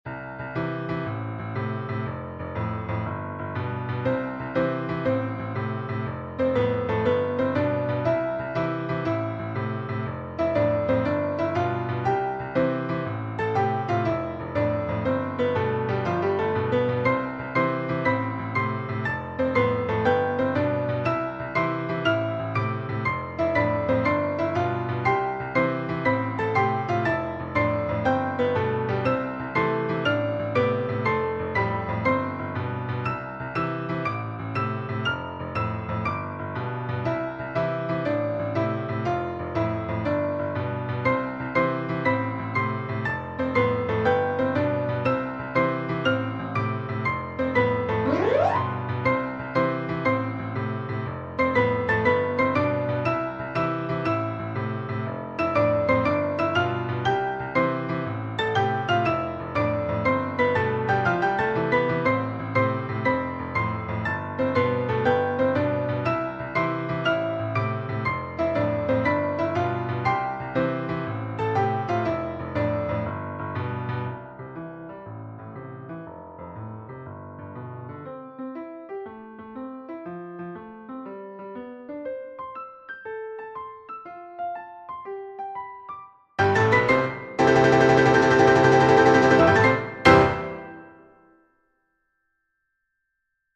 for 6 hands and 1 piano.